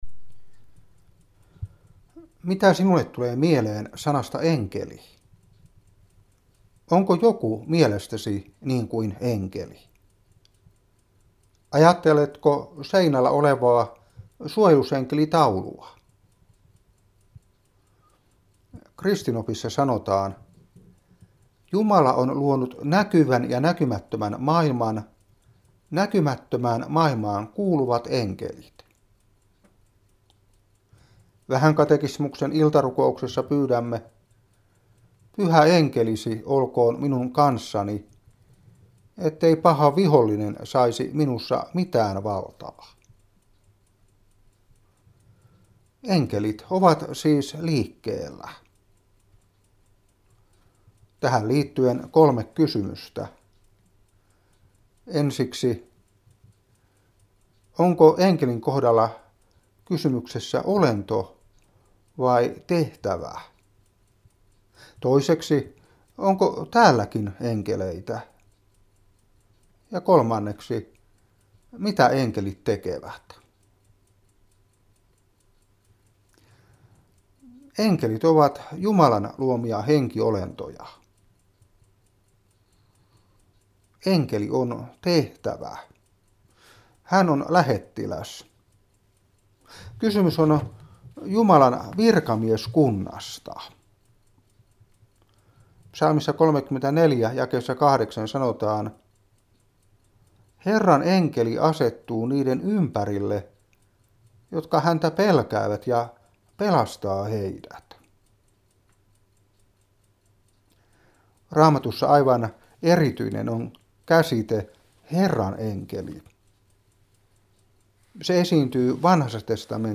Enkeli Toista max volume Lataa äänite Opetuspuhe 2015-10.